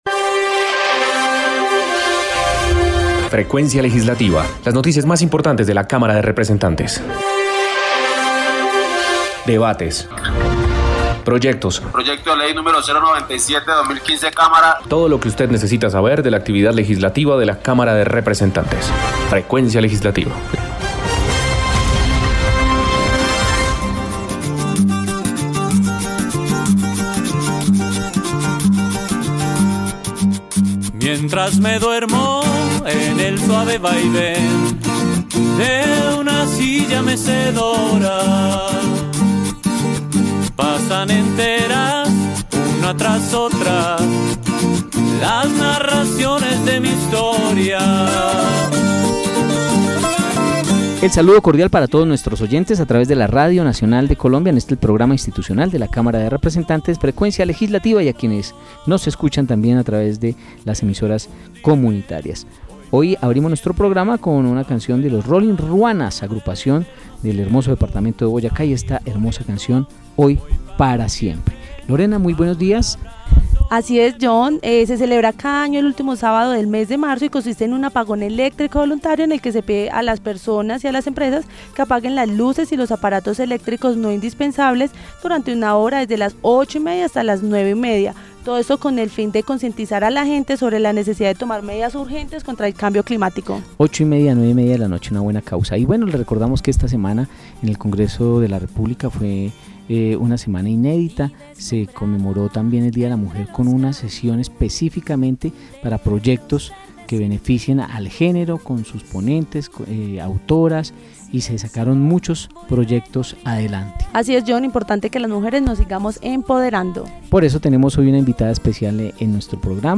Programa Radial Frecuencia Legislativa 27 de Marzo de 2021